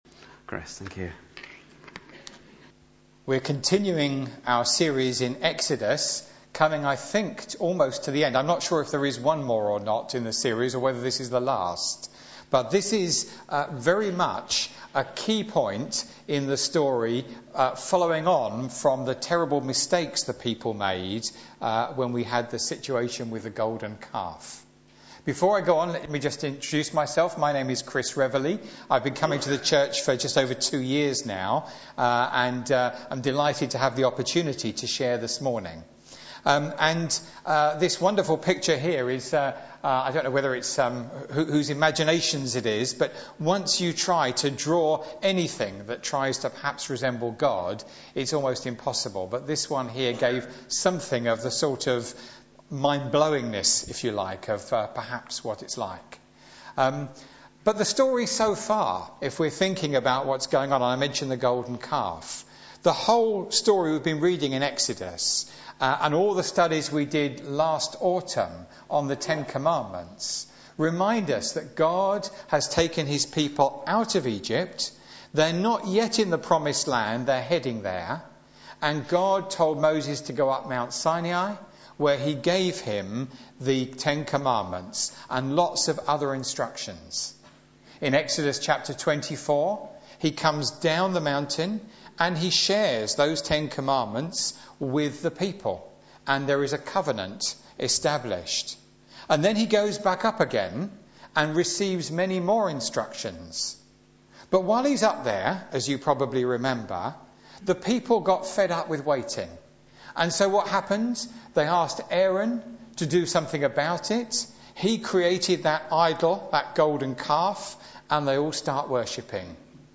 Media for Sunday Service
A Stiffed-Necked People Sermon